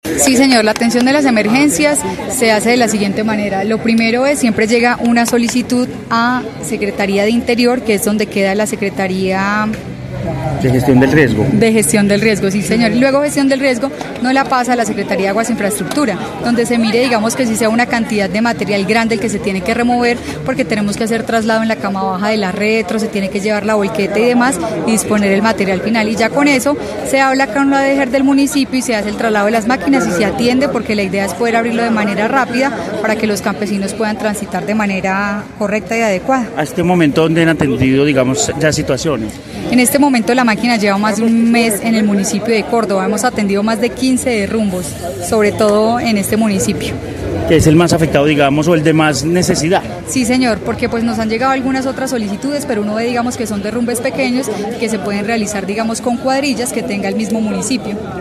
Vanessa Rincón, secretaria infraestructura, Quindío